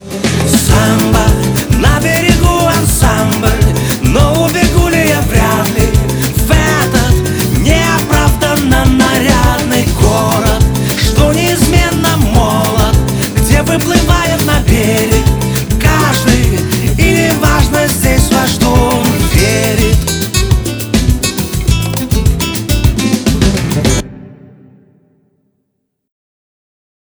Ref_human_warm_low.wav